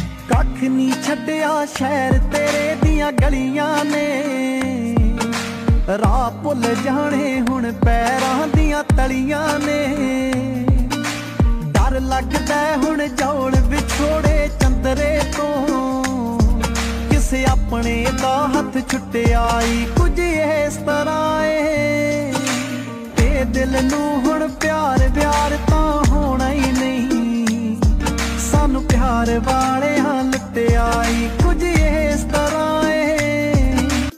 Punjabi Ringtones
Romantic Ringtones